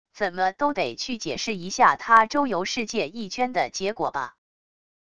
怎么都得去解释一下他周游世界一圈的结果吧wav音频生成系统WAV Audio Player